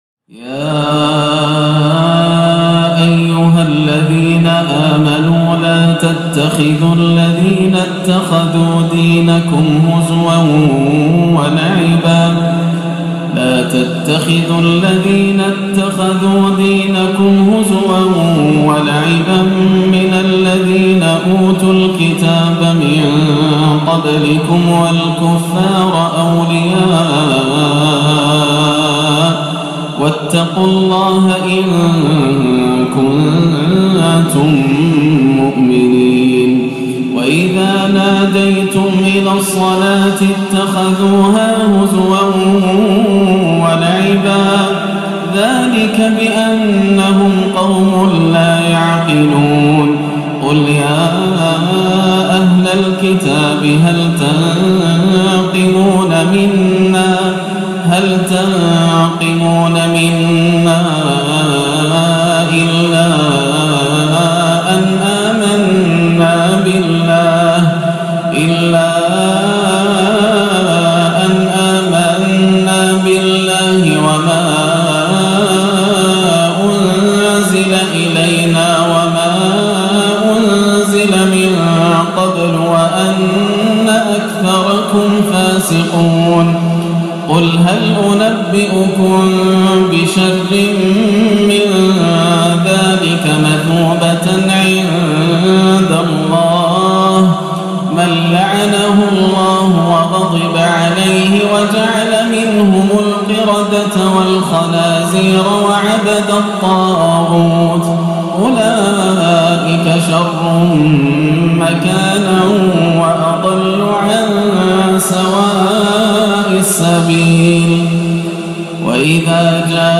(وَقَالَتِ الْيَهُودُ يَدُ اللَّهِ مَغْلُولَةٌ) تلاوة بديعة لما تيسر من سورة المائدة - الثلاثاء 7-5 > عام 1437 > الفروض - تلاوات ياسر الدوسري